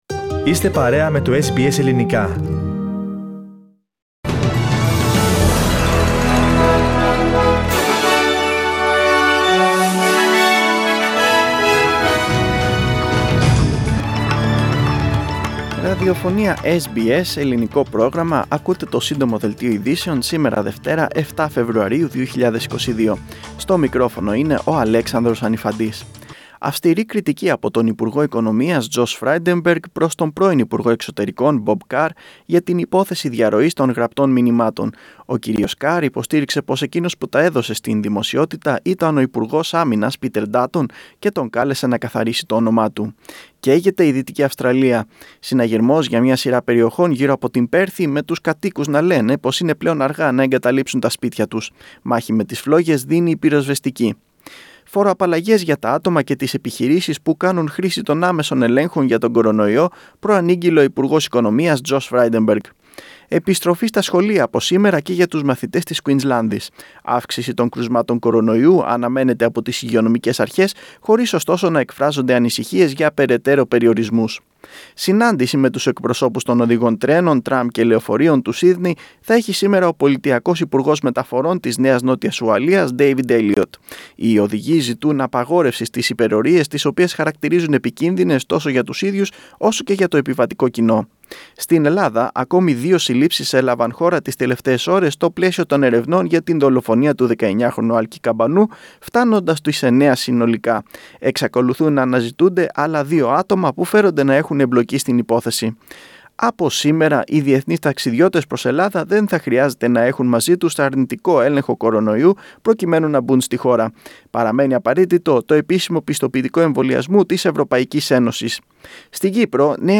News Flash - Σύντομο Δελτίο